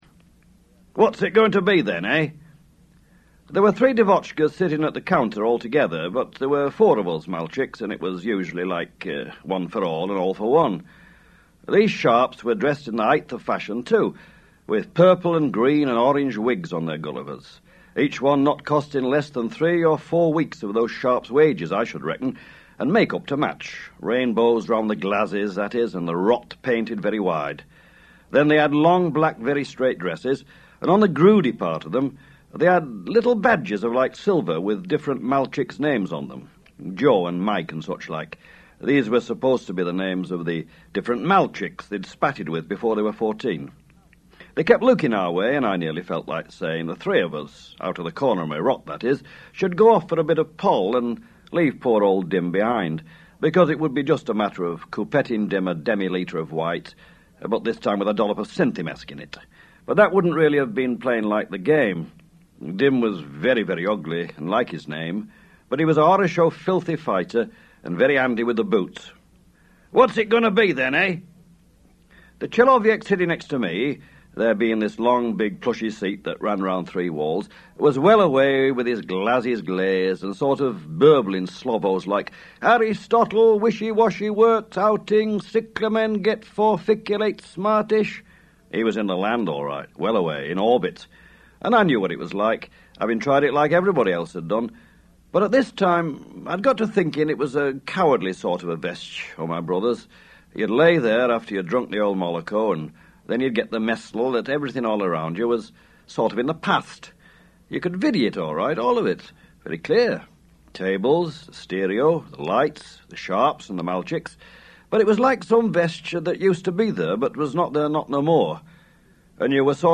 L'autore legge due estratti dal suo controverso romanzo
Con voce potente, l'autore interpreta, più che leggere semplicemente, estratti dai vari capitoli del romanzo.
burgess_reading_2.mp3